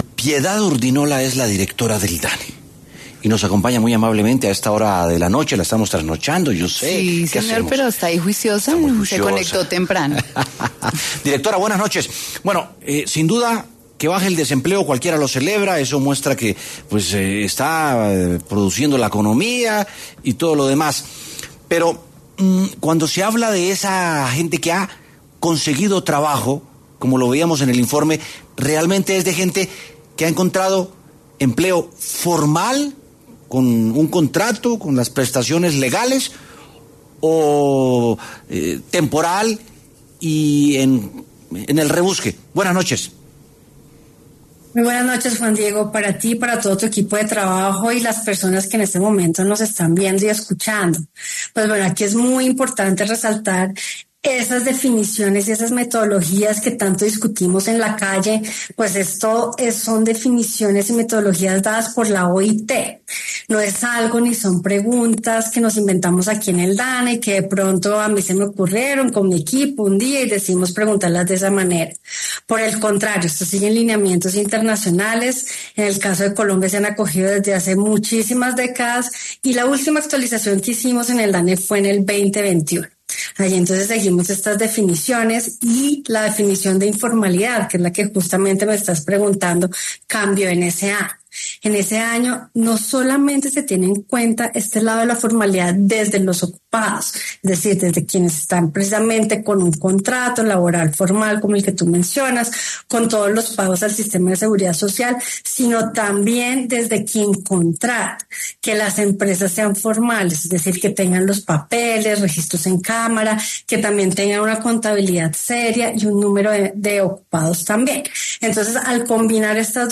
Piedad Urdinola, directora del Departamento Administrativo Nacional de Estadística (Dane), habló con W Sin Carreta tras el anuncio del Gobierno sobre la disminución en el desempleo.